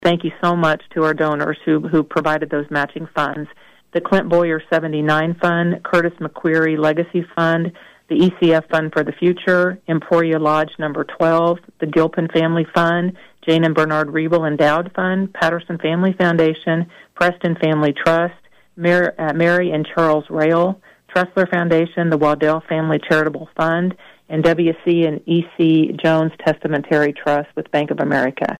During an interview on KVOE’s Talk of Emporia Friday